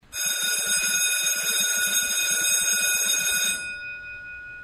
schoolbell.mp3